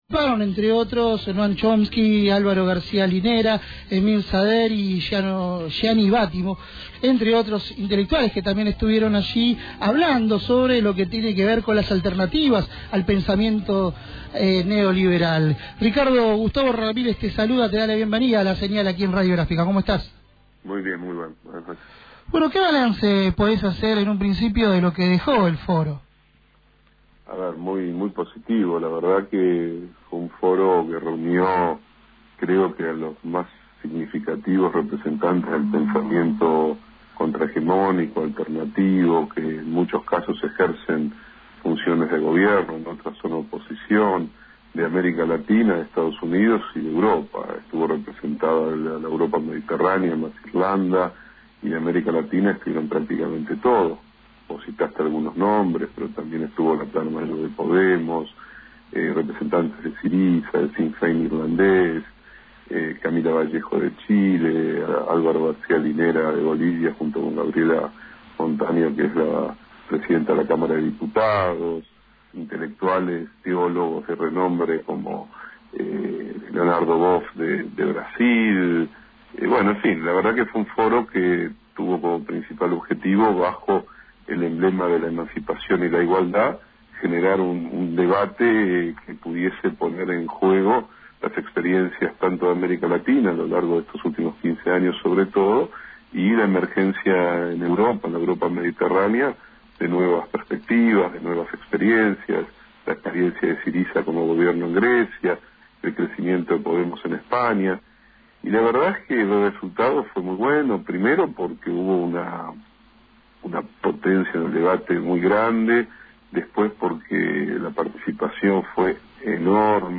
Entrevistado en La Señal, el reconocido filósofo brindó sus impresiones sobre las jornadas vividas en el Teatro Cervantes: «Estoy exultante.